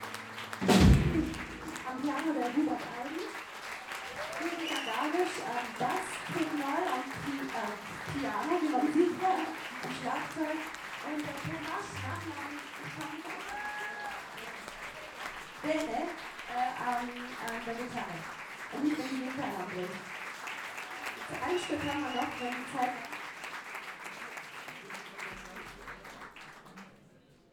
22 - Winelight - Ansage & Bandvorstellung.mp3